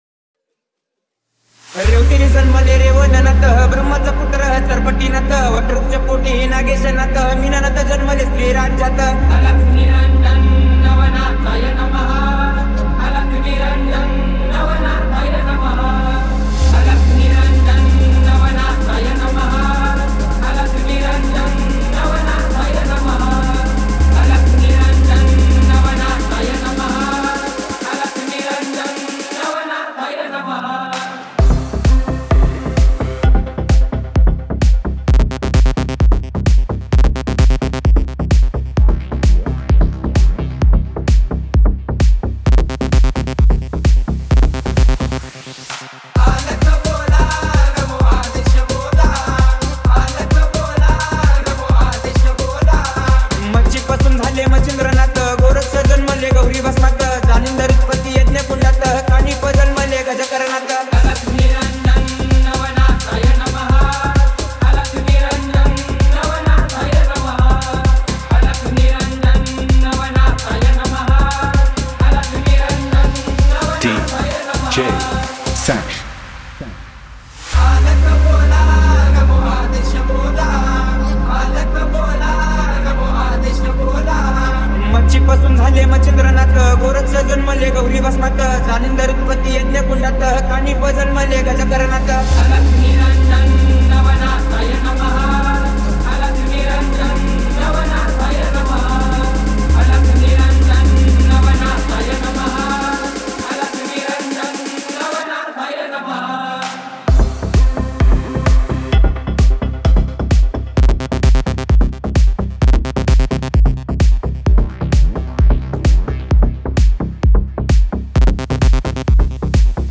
Category: MARATHI DJ